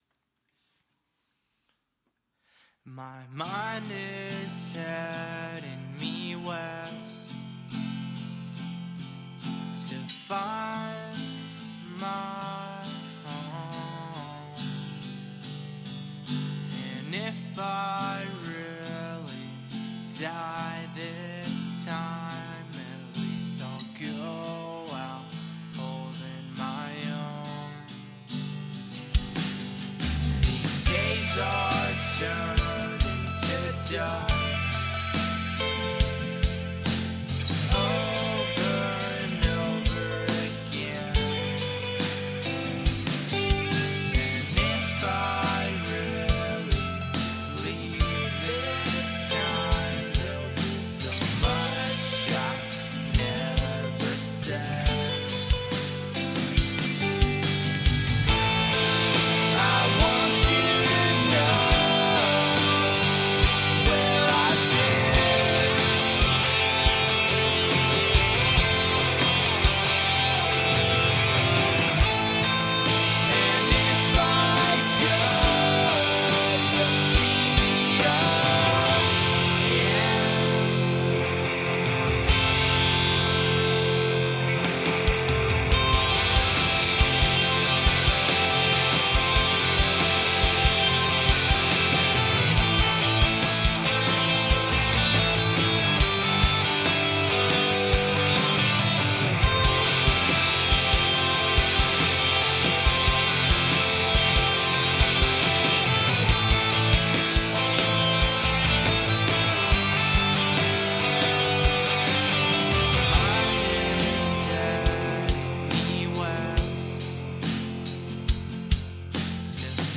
Check my folk/rock mix?
I will warn you, I haven't gotten around to finishing the lyrics so I copy and pasted the verses. I also plan on redoing all of the vocals anyways.
Attachments West rough mix with vocals 2.mp3 West rough mix with vocals 2.mp3 4.2 MB · Views: 58